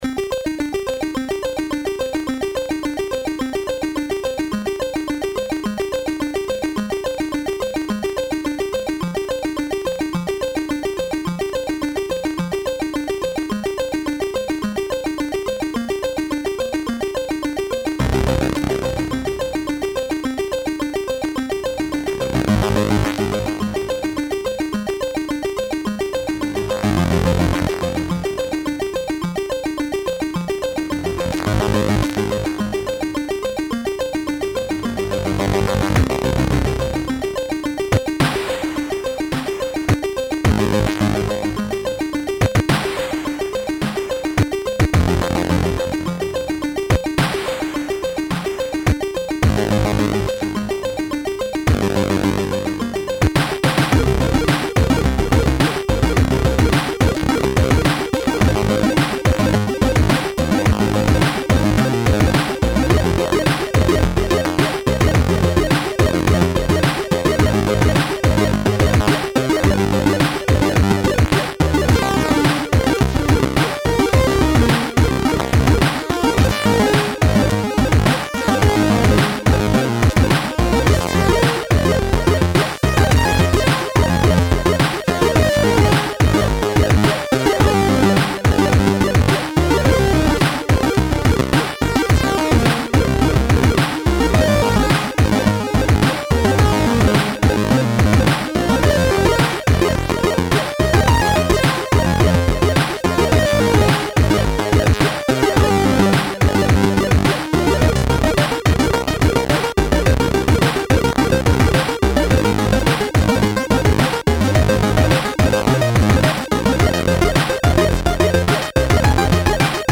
A remix of an awesome tune on the Commodore 64